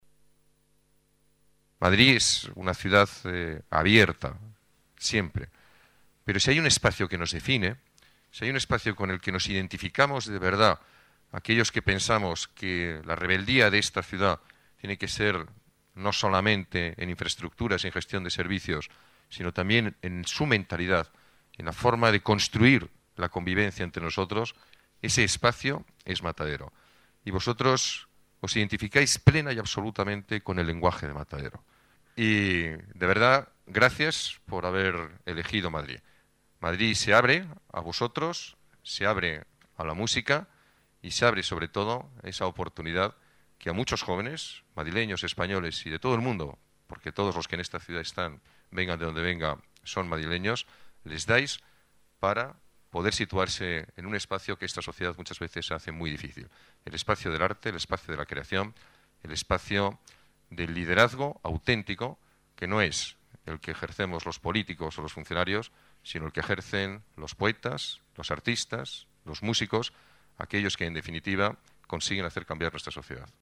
Nueva ventana:Alcalde en presentación de Red Bull Music